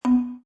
I made an mp3 out of the
standard windows ding sound.
ding.mp3